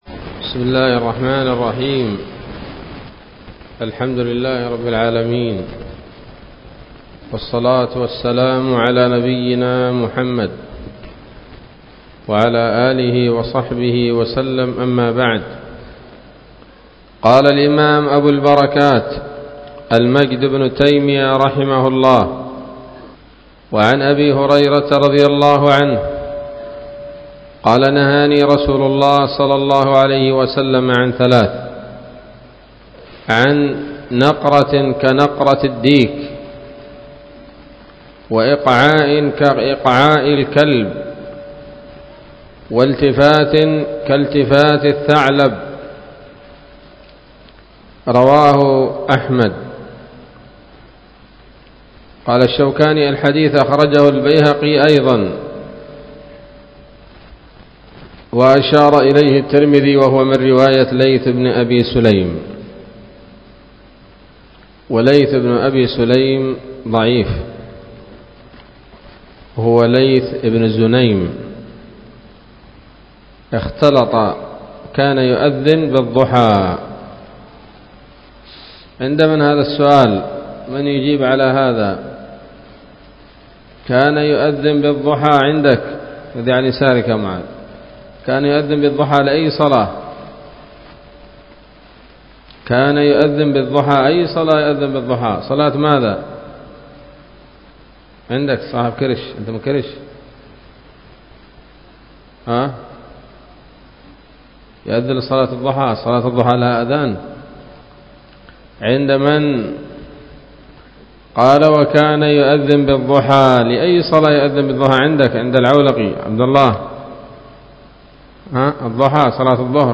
الدرس الرابع والسبعون من أبواب صفة الصلاة من نيل الأوطار